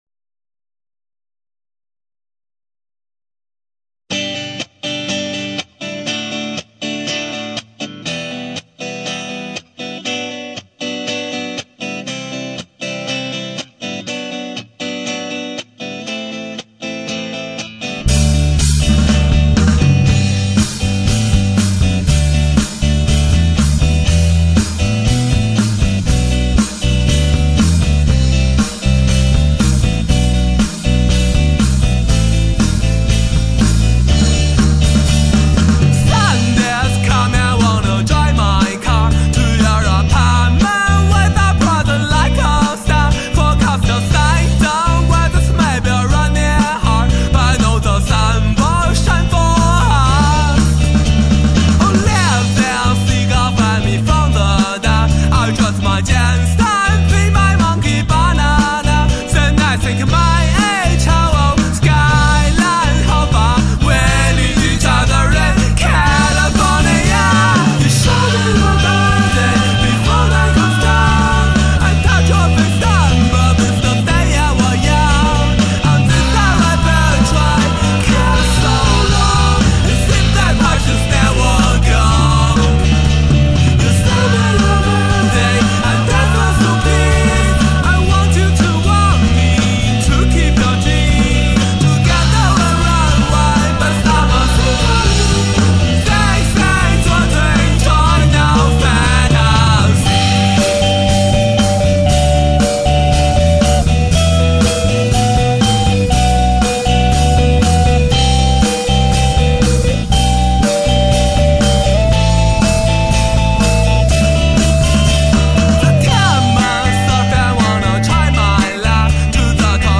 这是首英伦格式的歌，在高速路上阳光明媚时听最合适。
不过这是个北京的乐队